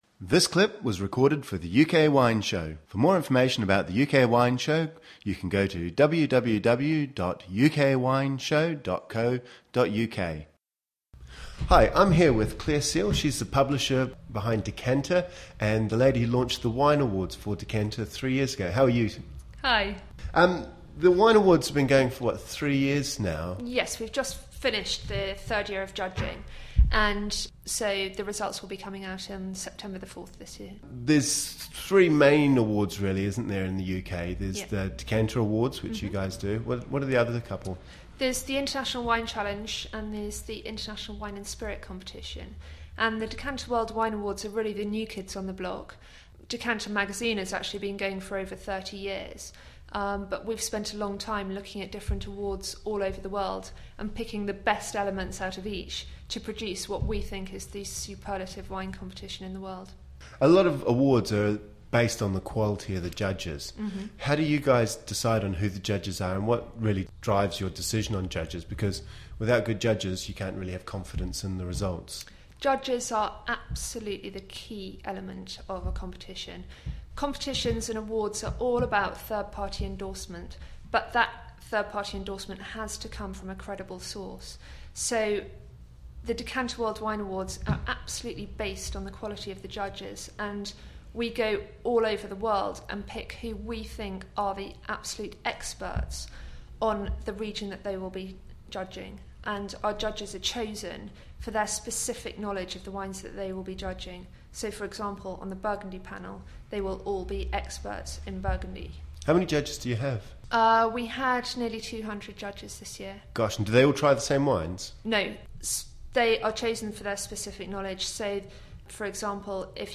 The music used for the UK Wine Show is Griffes de Jingle 1 by Marcel de la Jartèle and Silence by Etoile Noire.